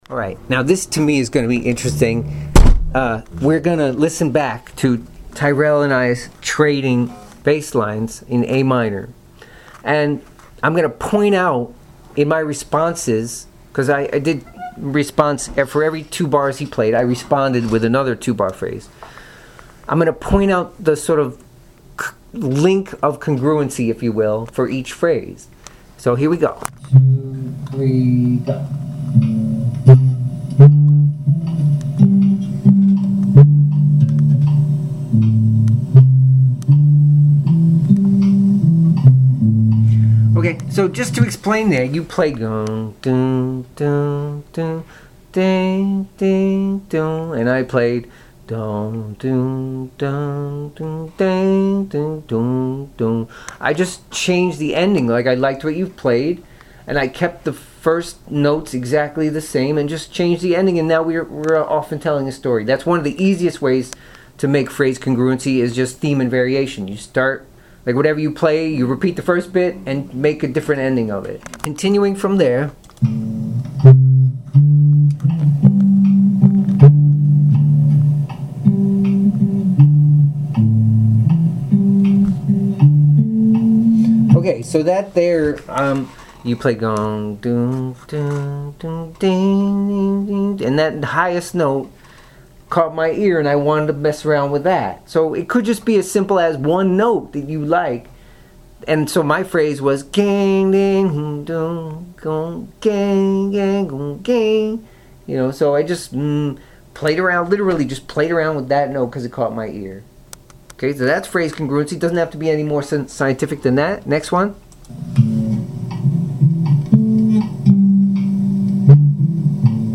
analysis-of-phrase-congruency-bass-lines.mp3